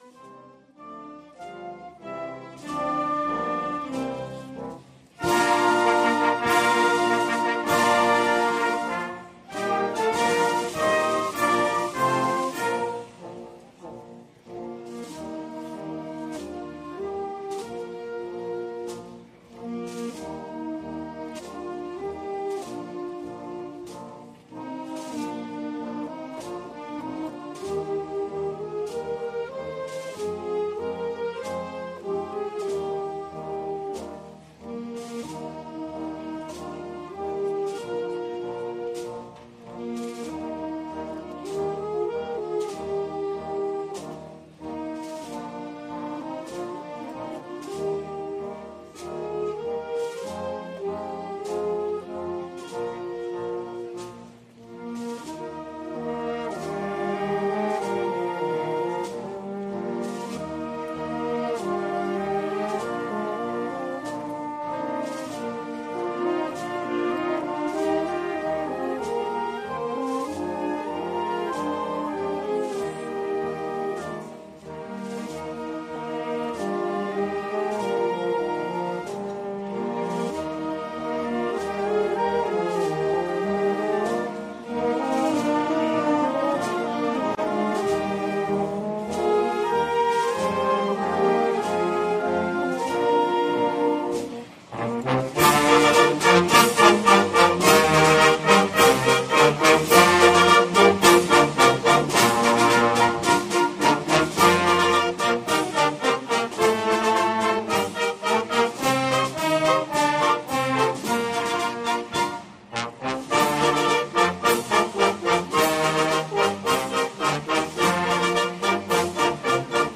Audio: Acto del preg�n de la Semana Santa 2019 (MP3 - 44,60 MB)
Arranca una de la más antigua y apasionante de las tradiciones cartageneras como es la Semana Santa , declarada de Interés Turístico Internacional . La sala Isidoro Maiquez del Auditorio Municipal de El Batel ha acogido por segundo año consecutivo, este sábado, día 9 de marzo , el pregón de Semana Santa de Cartagena .